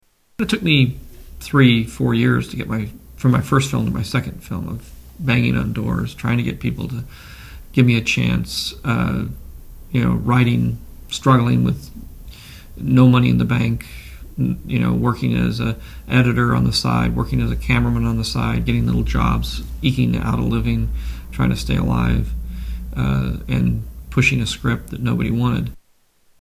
Tags: Media George Lucas audio Interviews George Lucas Star Wars Storywriter